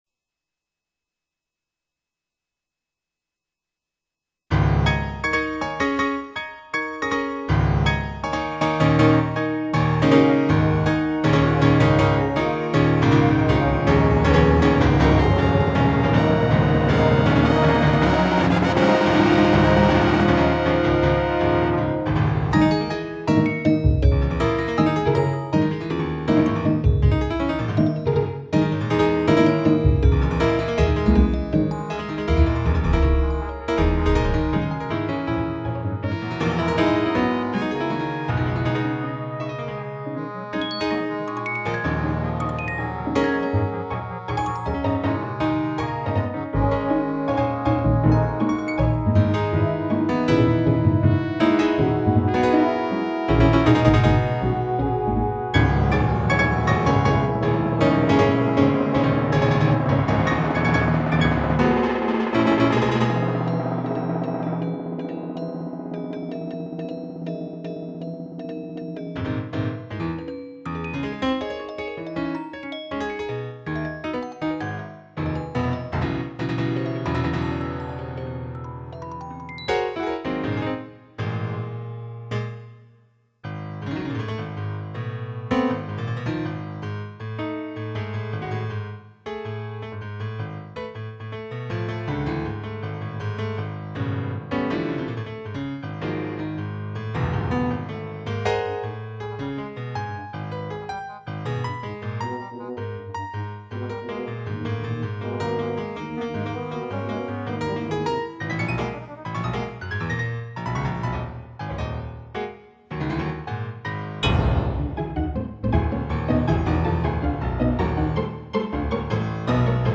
Piano Concerto
SYMPHONIC MUSIC